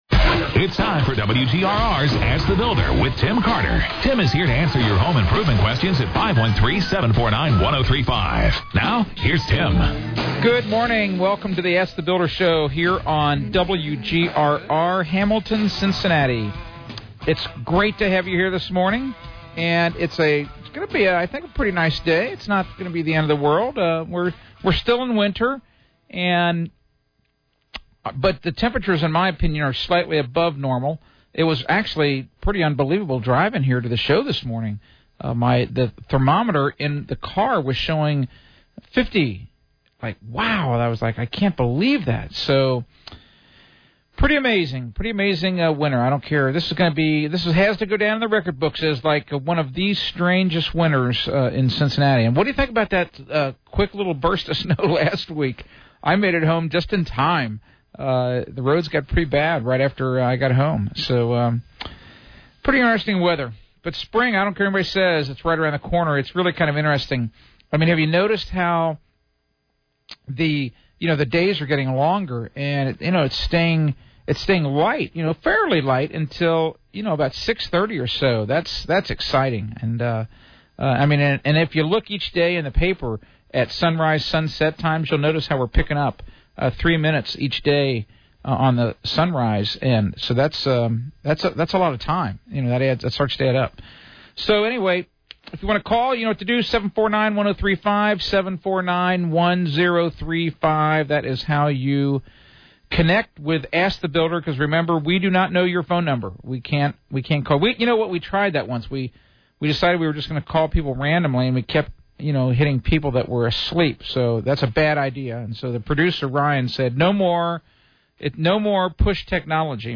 Askthebuilder radio show
Q&A /